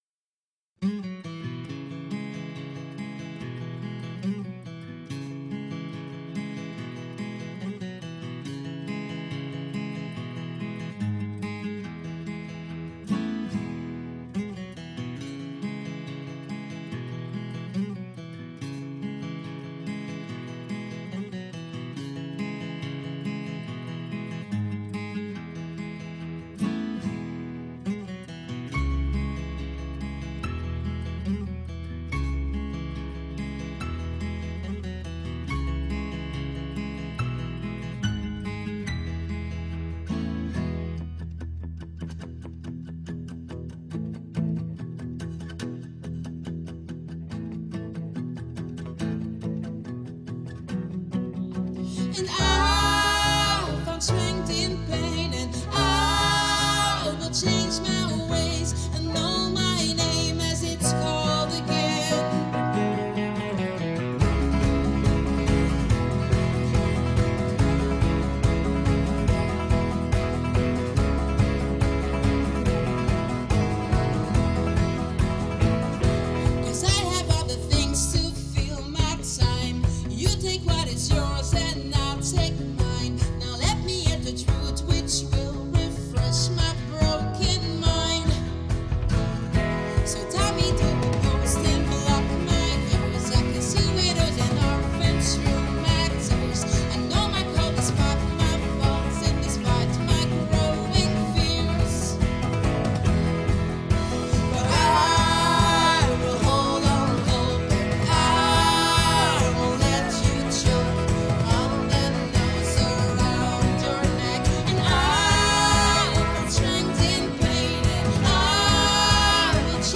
Alle Stemmen